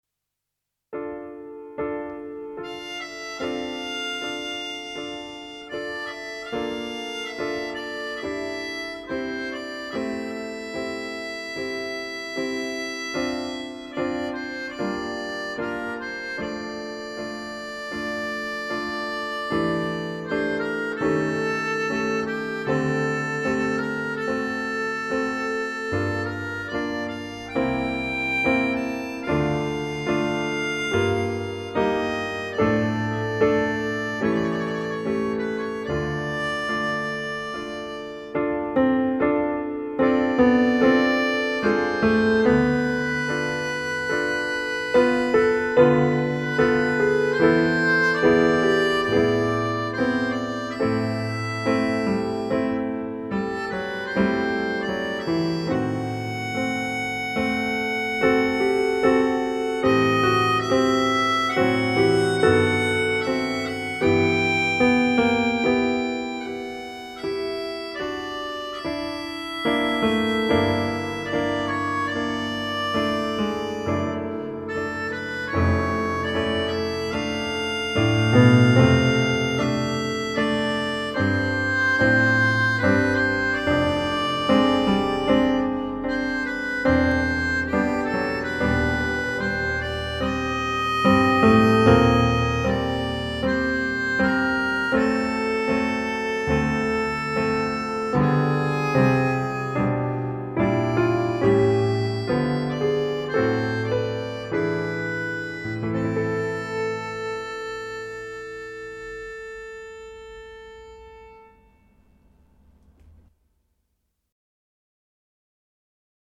Melodion
Harmonica
and Qchord